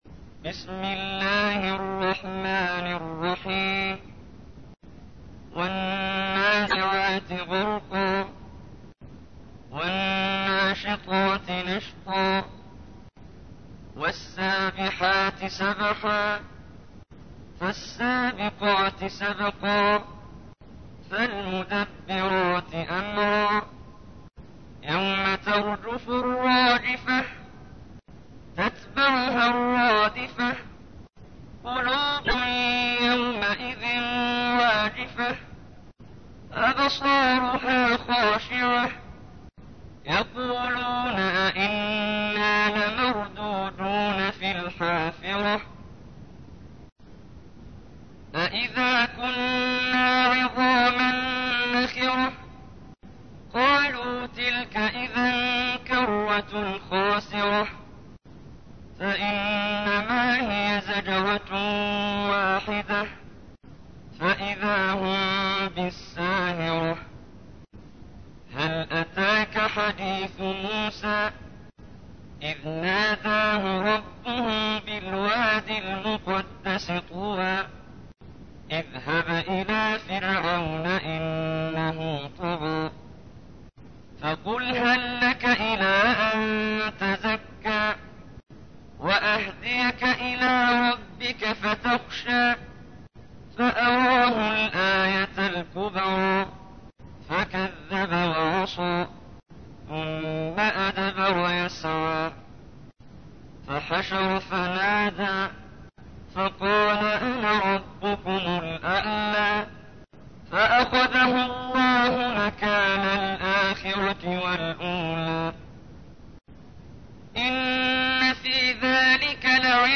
تحميل : 79. سورة النازعات / القارئ محمد جبريل / القرآن الكريم / موقع يا حسين